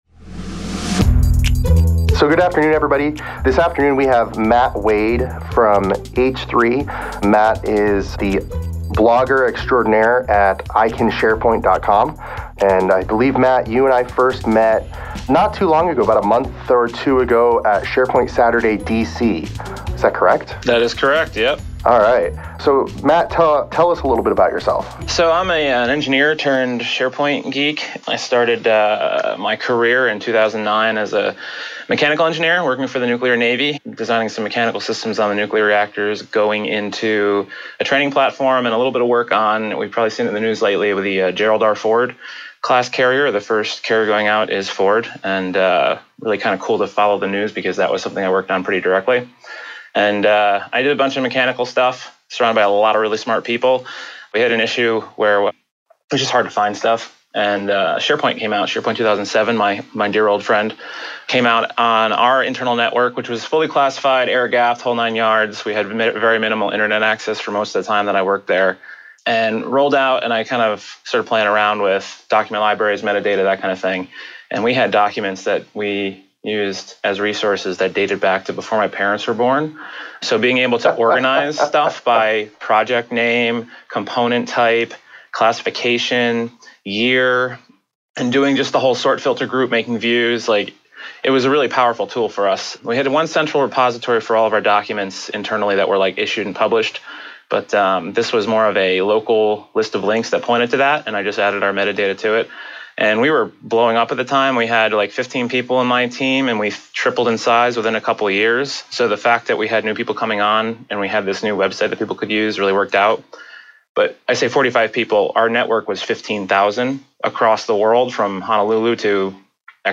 This edited podcast was recorded Saturday July 28, 2017.